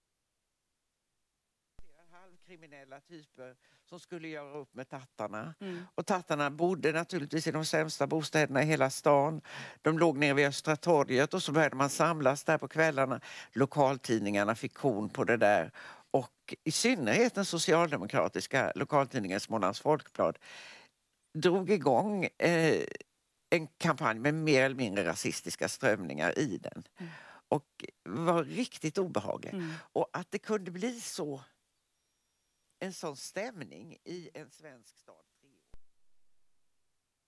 Rösten tillhör författaren Majgull Axelsson som i gårdagens Go’kväll talade om zigenarna (tattarna) i Sverige på 40-talet.